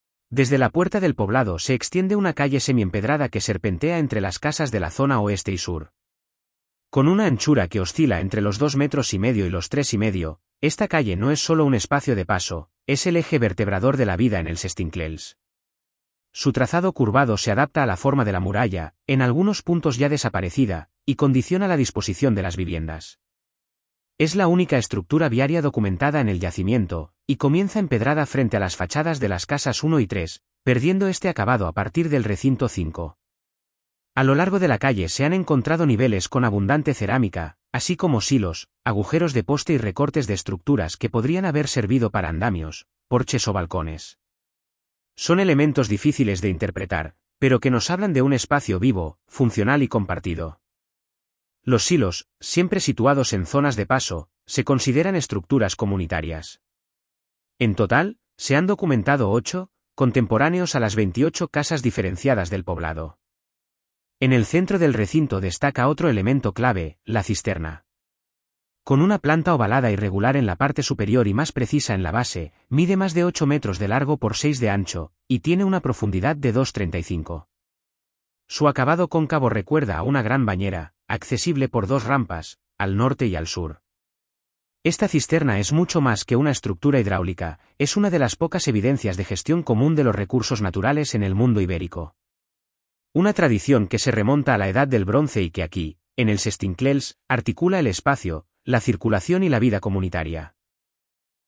Audioguía Calle circular y balsa